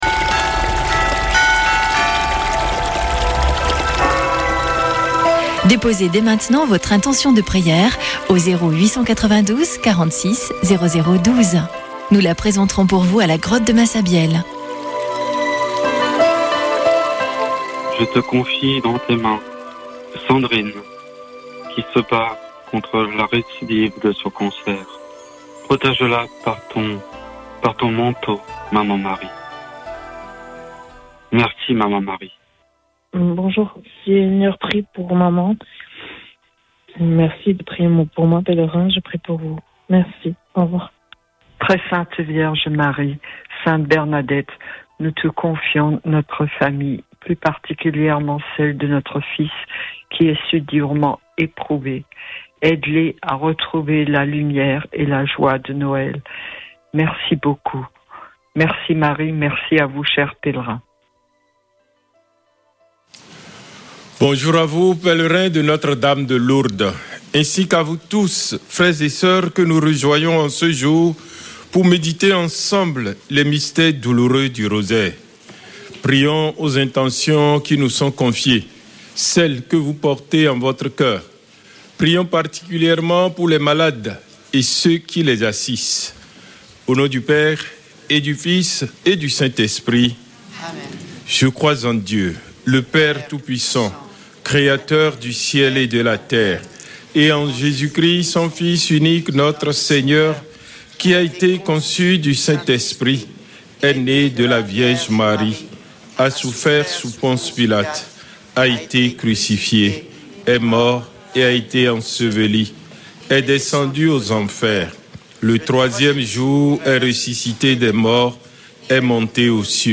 Chapelet de Lourdes du 16 déc.